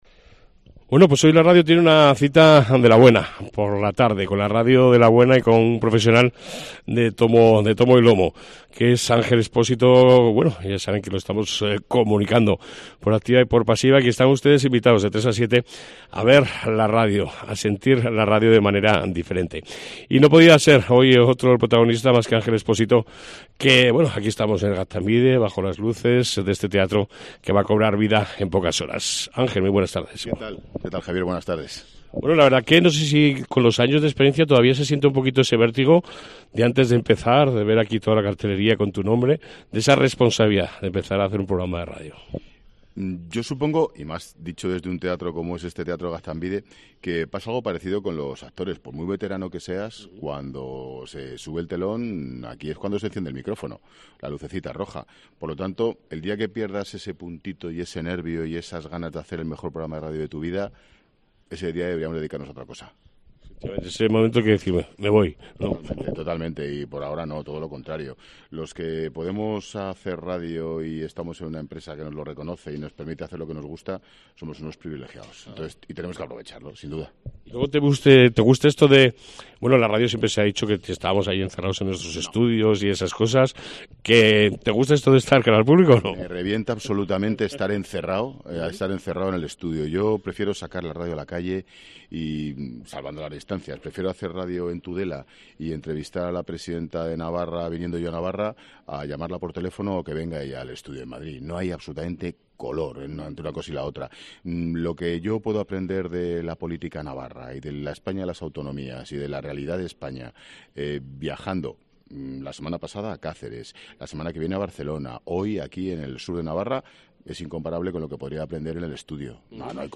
Entrevista con D.Ángel Expósito ante la emisión de "La Tarde de Cope" desde Tudela.